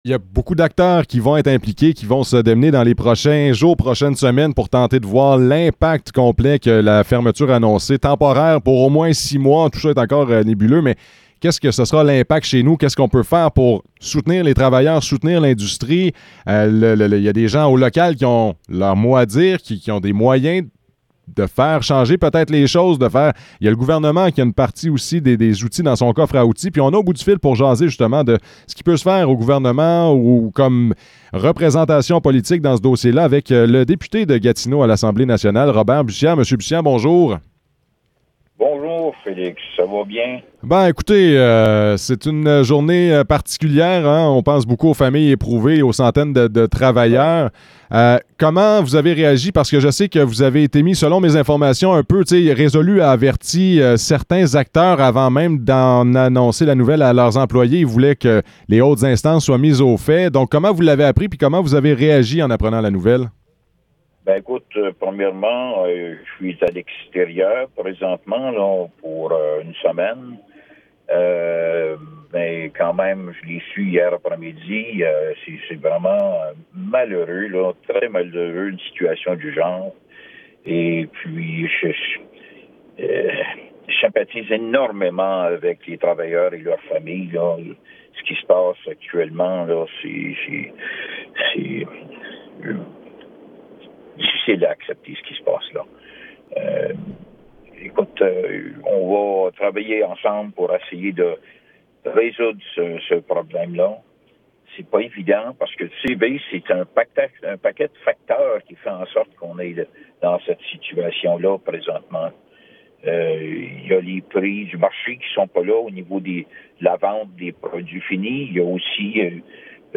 Entrevue avec Robert Bussière
entrevue-avec-robert-bussiere-2.mp3